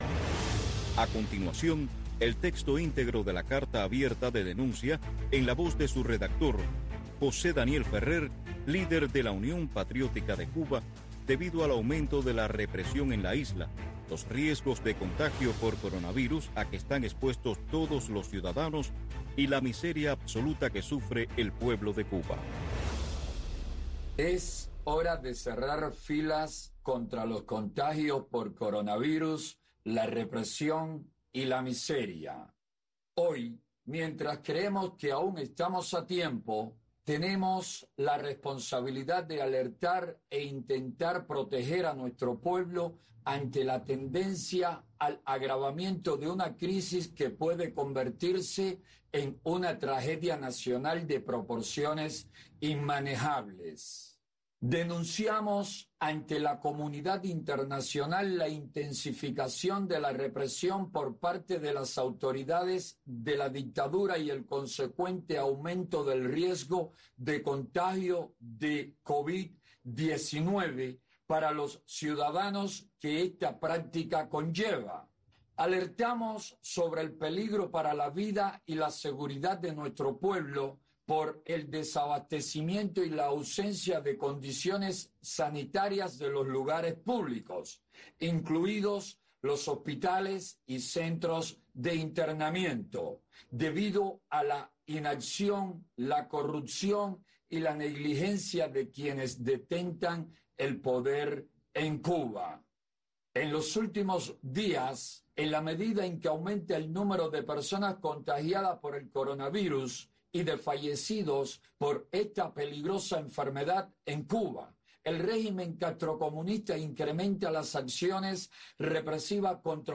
Lawton Libre es el programa conducido por el Doctor Oscar Elías Biscet que te habla de los derechos humanos, de las libertades básicas y de cómo lograr la libertad, tu libertad, porque si aprendes a ser libre todos los seremos Todos los sábados a las 7 am y también los sábados y domingos a las 11 de la noche en Radio Martí.